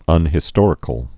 (ŭnhĭ-stôrĭ-kəl, -stŏr-)